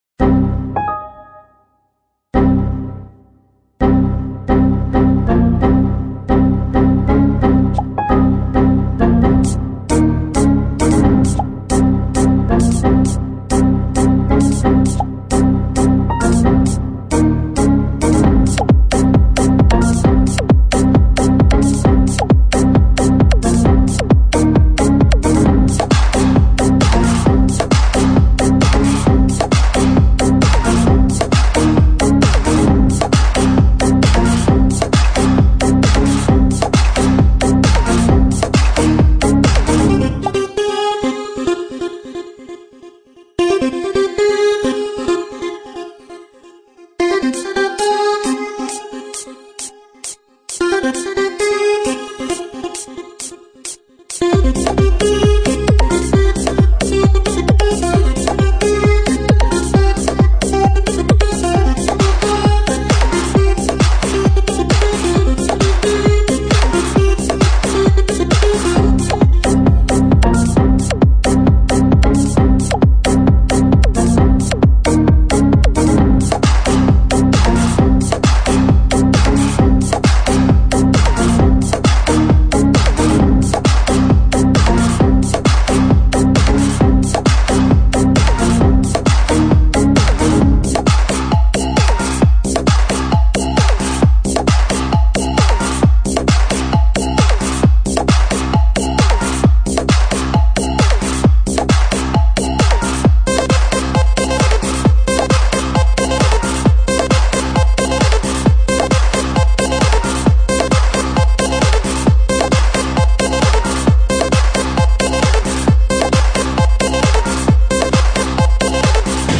[9/2/2009]冲击你的电脑 ☞ 叫人心惊肉跳的超节奏慢摇 激动社区，陪你一起慢慢变老！